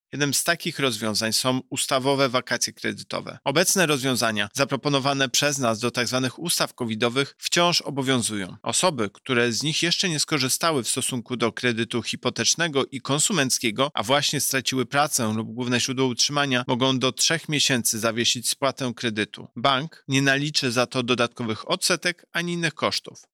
• mówi Tomasa Chróstny, prezes UOKiK.